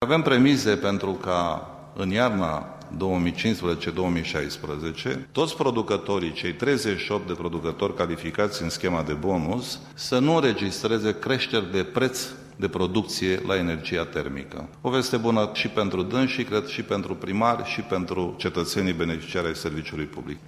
Vicepreşedintele ANRE, Emil Calotă :